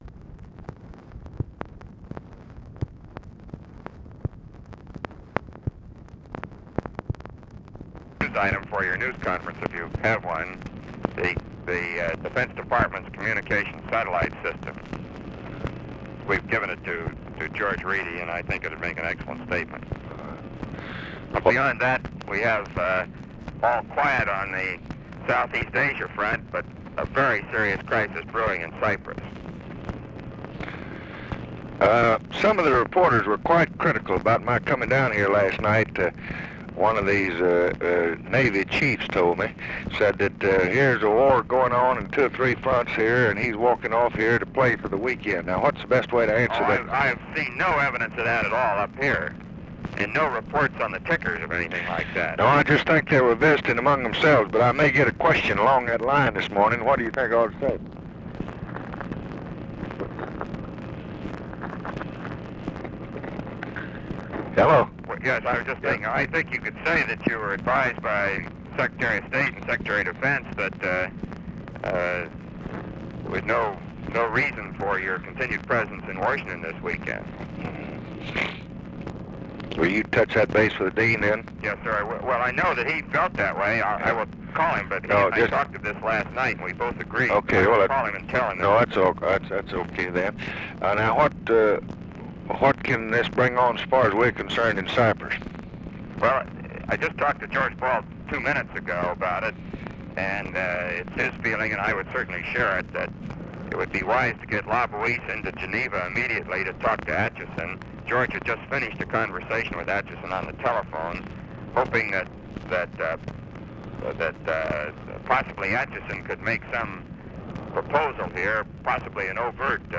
Conversation with ROBERT MCNAMARA, August 8, 1964
Secret White House Tapes